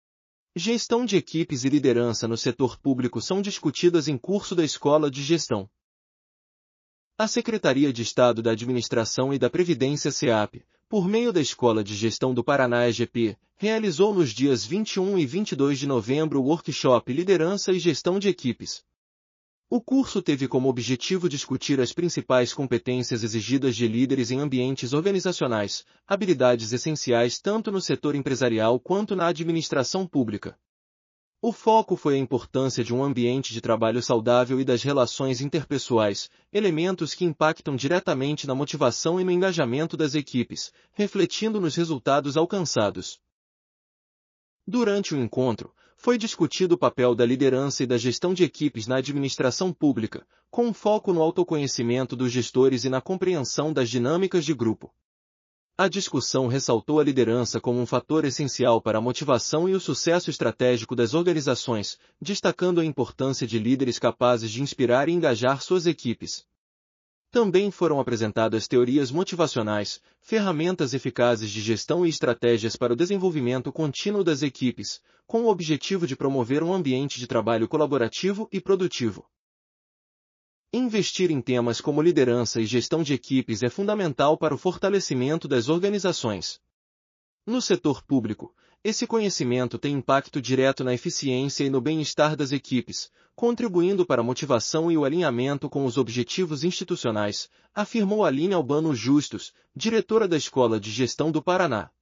audionoticia_lideranca_no_setor_publico.mp3